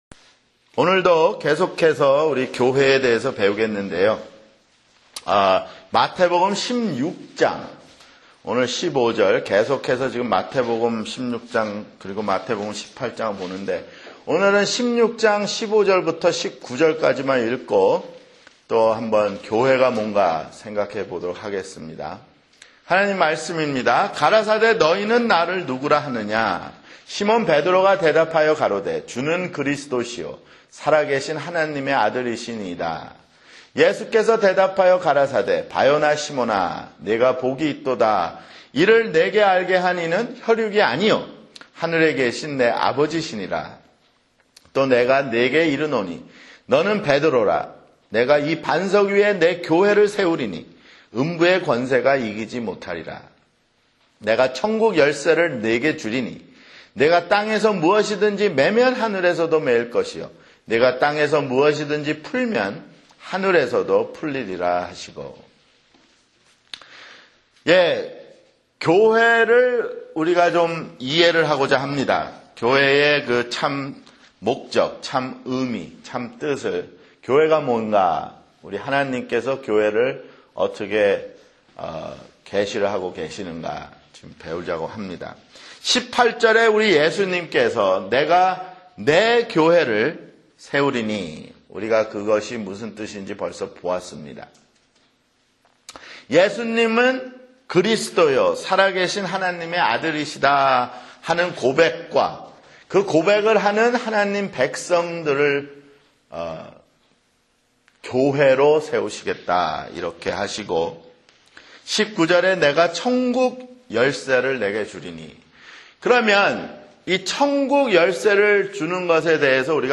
[성경공부] 교회 (4)